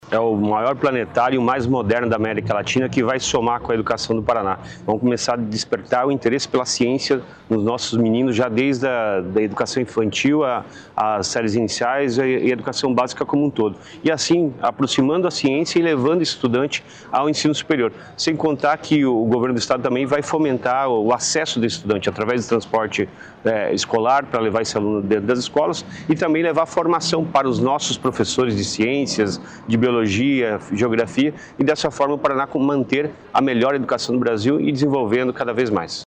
Sonora do secretário Estadual da Educação, Roni Miranda, sobre a assinatura da parceria com empresa alemã Carl Zeiss